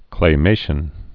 (klā-māshən)